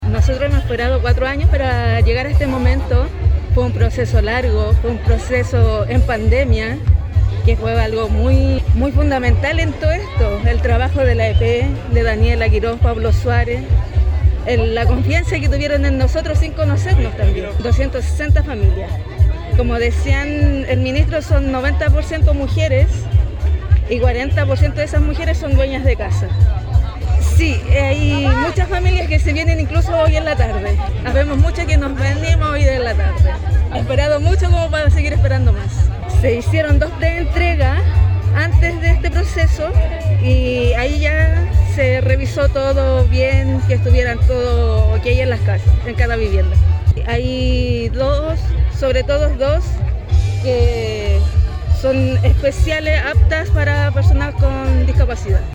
El sueño de la vivienda propia para 260 familias de Melipilla ya es realidad, tras la ceremonia de entrega del proyecto habitacional “Sol Poniente de Melipilla”.